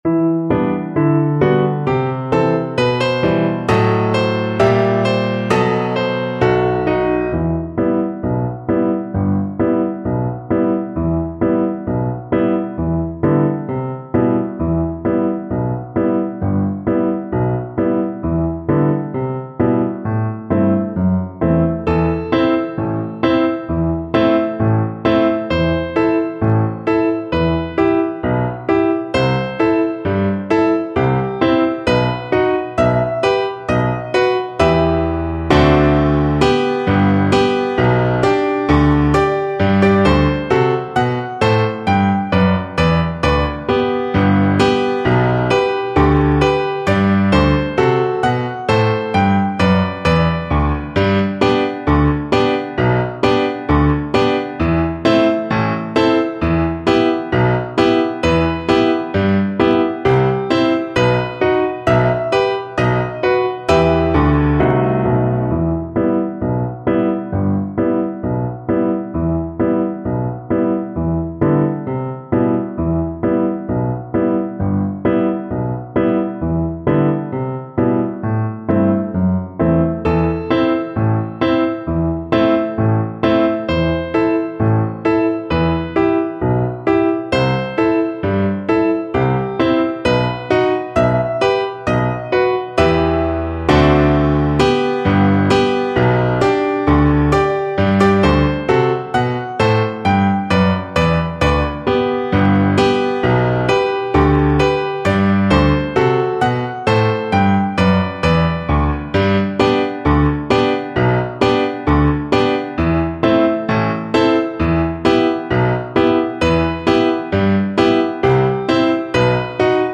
Cello
E minor (Sounding Pitch) (View more E minor Music for Cello )
4/4 (View more 4/4 Music)
With energy =c.100
Traditional (View more Traditional Cello Music)
Israeli